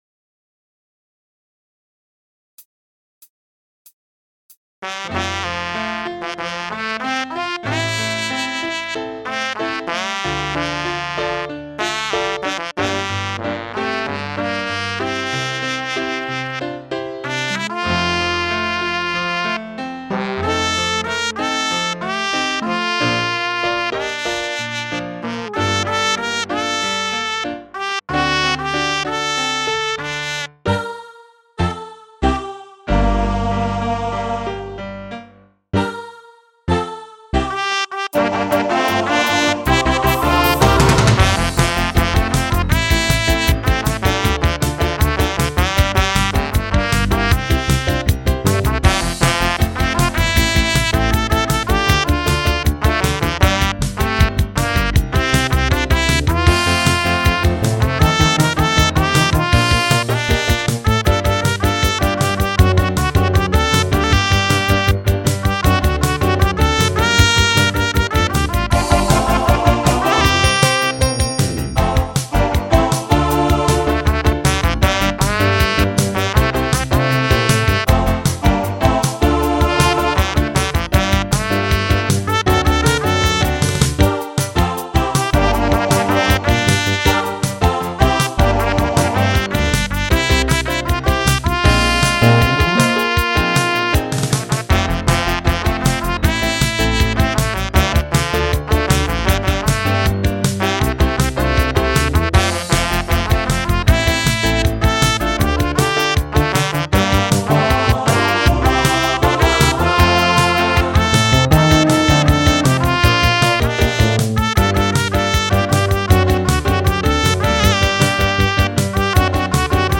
an "instrumental" if you will